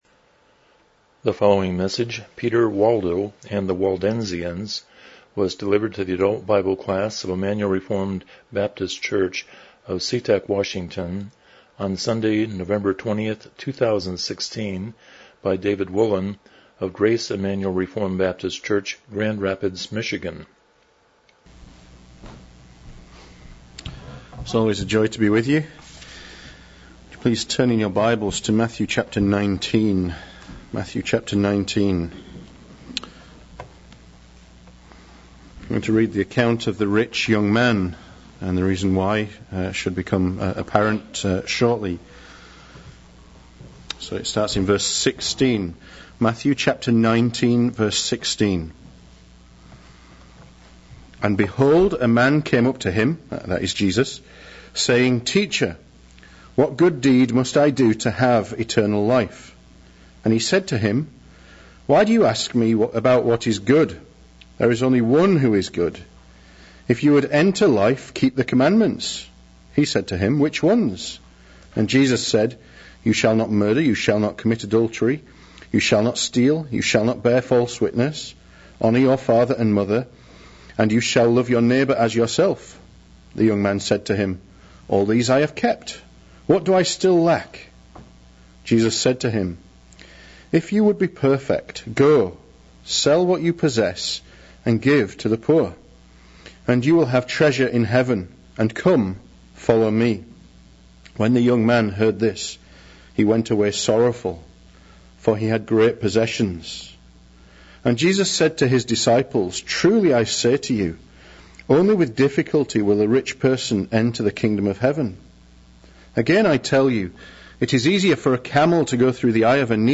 Miscellaneous Service Type: Sunday School Topics